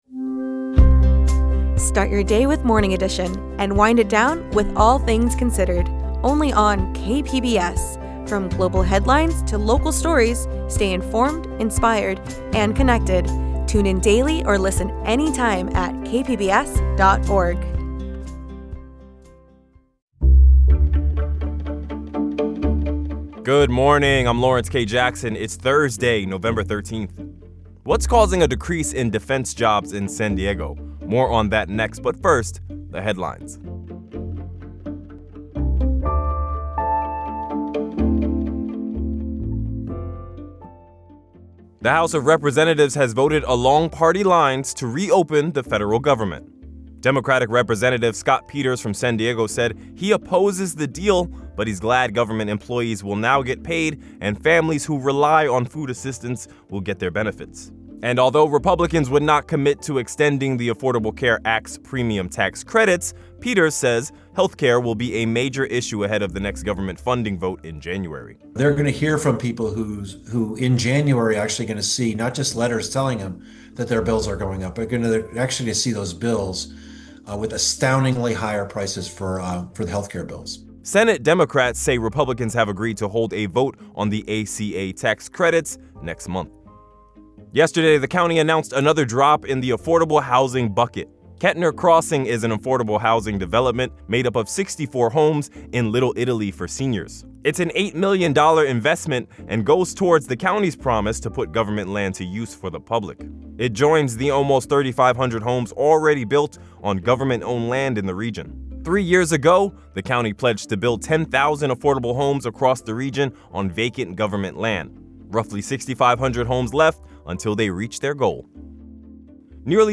N Finally, an interview on the American Revolution and its relevance to today’s struggles to preserve American Democracy.